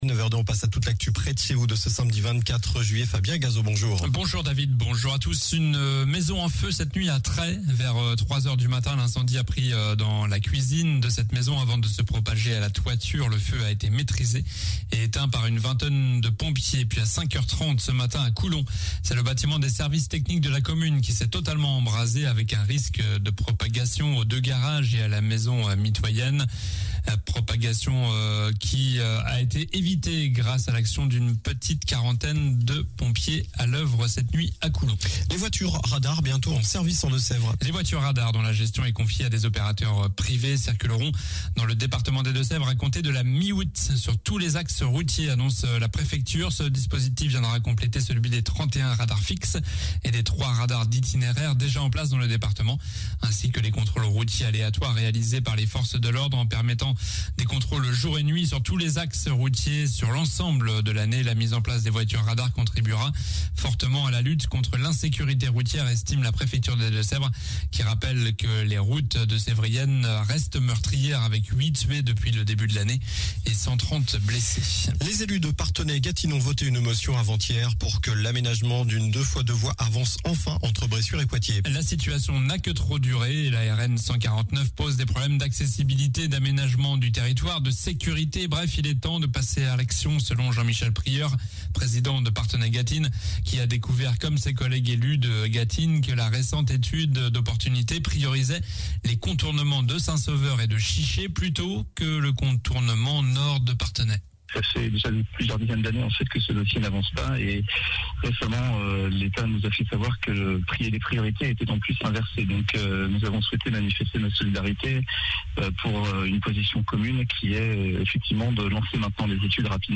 Journal du samedi 24 juillet (matin)